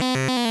game_over.wav